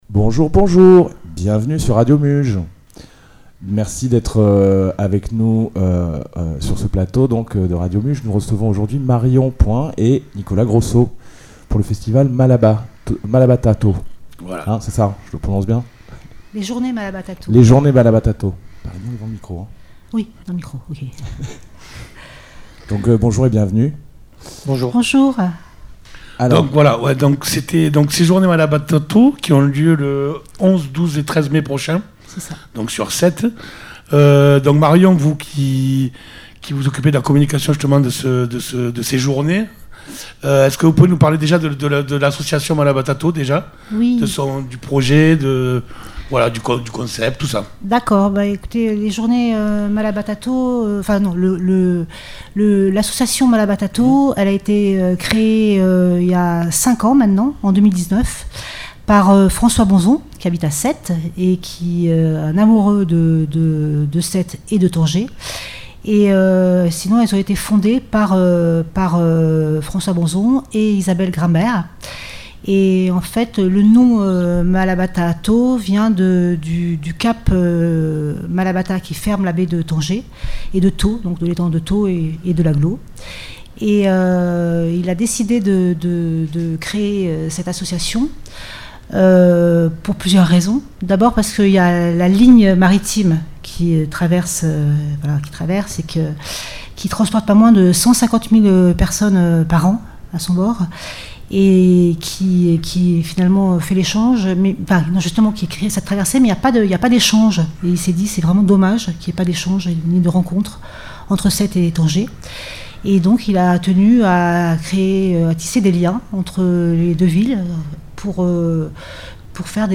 La rencontre a été enregistrée dans les studios de Radio Muge le mercredi 3 Mai 2023.